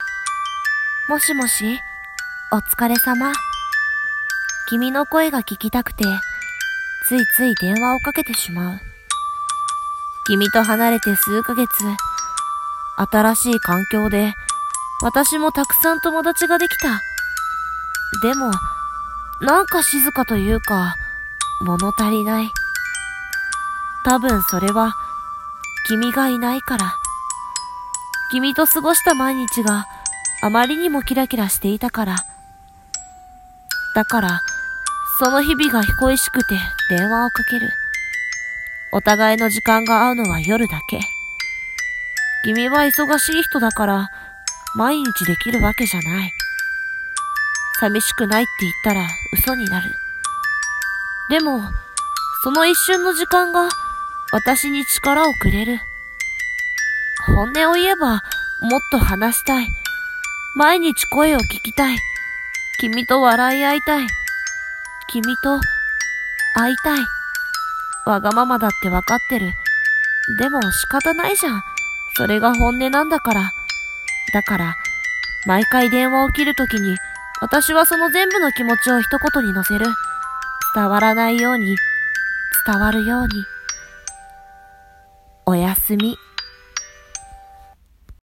【一人声劇】おやすみ【台本】